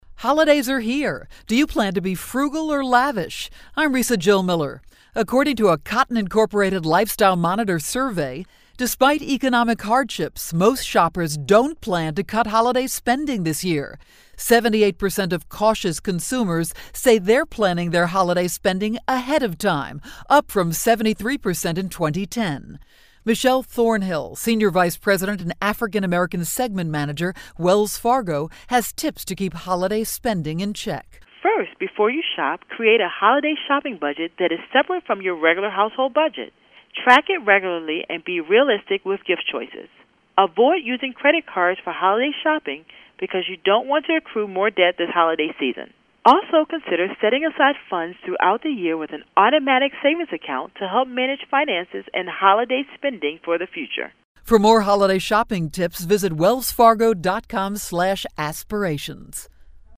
December 8, 2011Posted in: Audio News Release